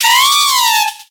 Grito de Servine.ogg
Grito_de_Servine.ogg